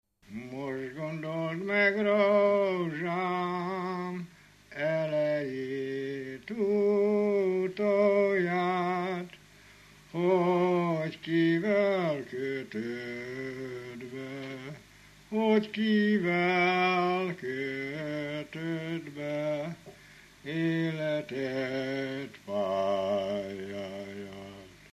Felföld - Zemplén vm. - Nagyrozvágy
Műfaj: Lakodalmas
Stílus: 5. Rákóczi dallamkör és fríg környezete
Szótagszám: 6.6.6.6.6